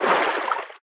Sfx_creature_seamonkeybaby_swim_fast_01.ogg